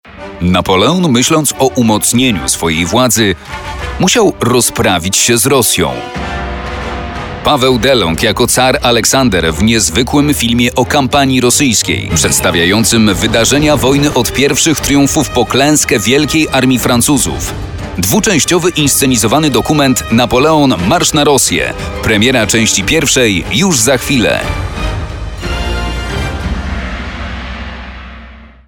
Male 30-50 lat
Very clear voice in mid-range registers.
Nagranie lektorskie